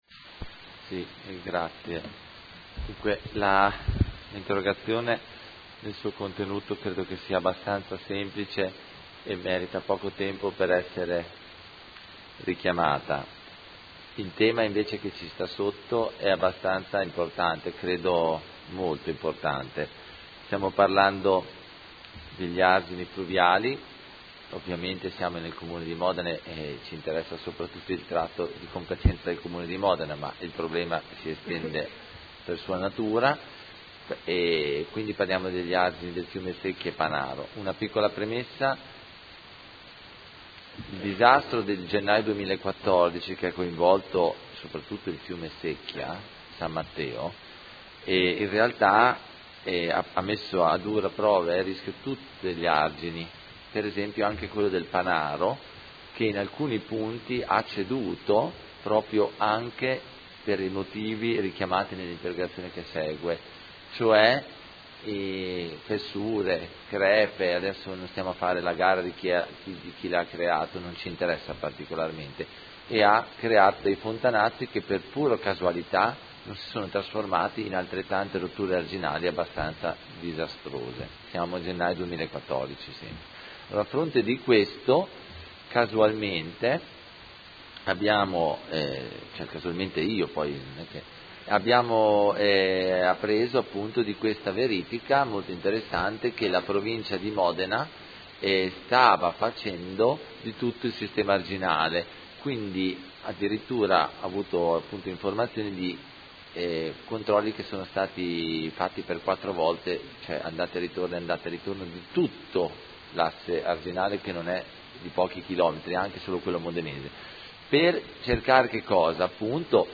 Seduta del 22 ottobre. Interrogazione del Consigliere Carpentieri (P.D.) avente per oggetto: Interventi AIPO per la messa in sicurezza degli argini fluviali